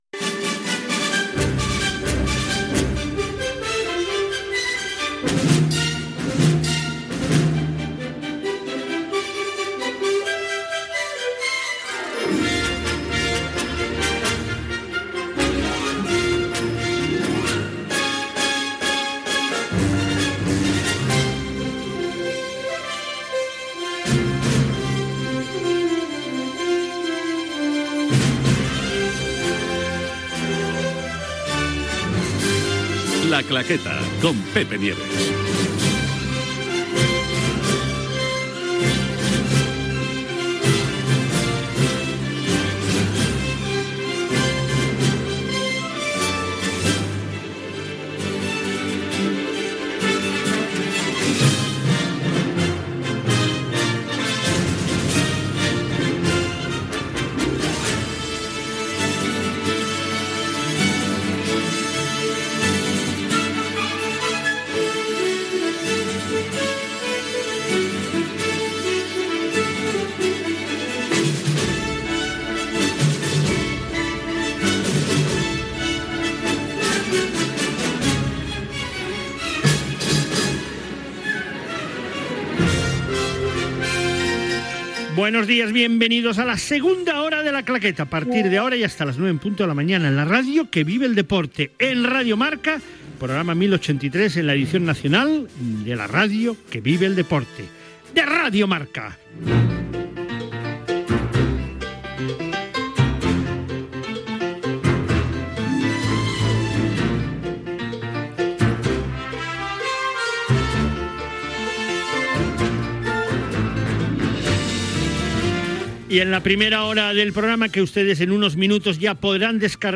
Indicatiu del programa, resum del fet a la primera hora, salutació als col·laboradors del programa i primeres intervencions seves.